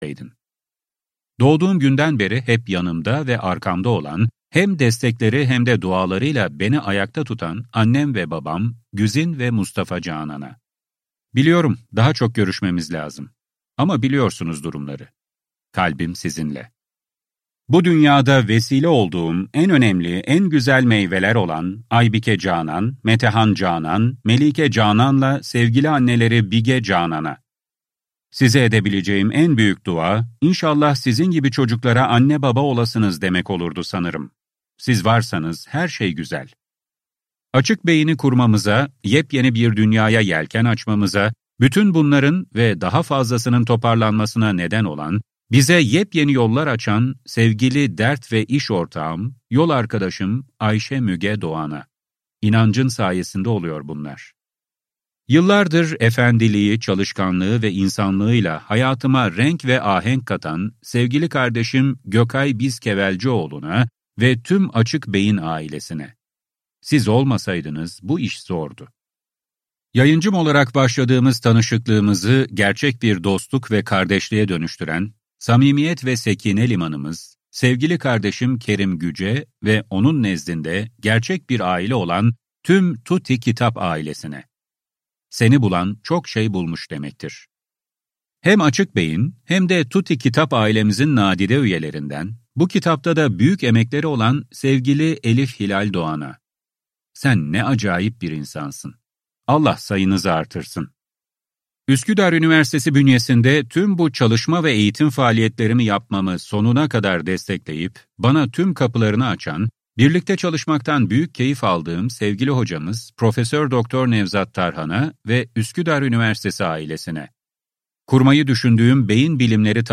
Kitap: Beden - Seslenen Kitap